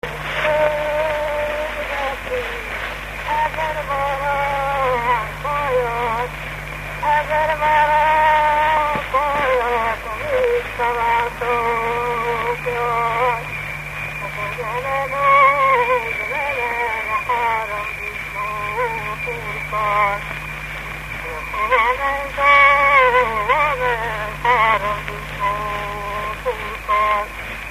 Moldva és Bukovina - Moldva - Somoska
ének
Gyűjtő: Veress Sándor
Stílus: 3. Pszalmodizáló stílusú dallamok